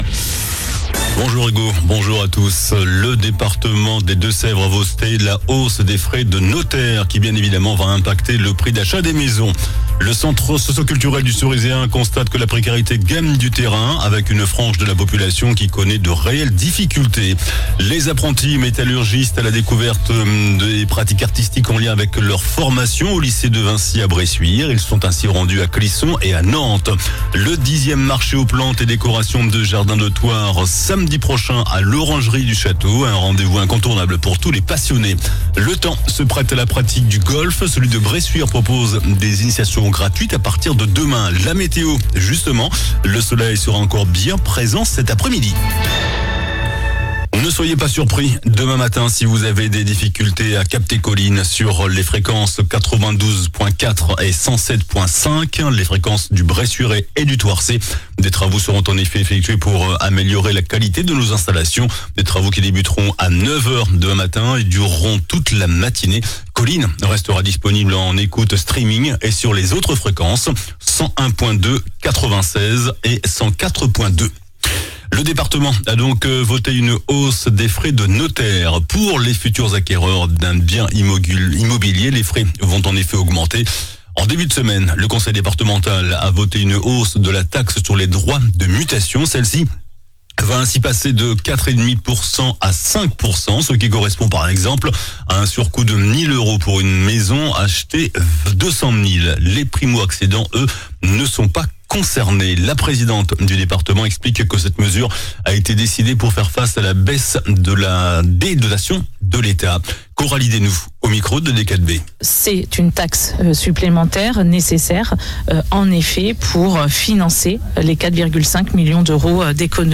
JOURNAL DU MERCREDI 09 AVRIL ( MIDI )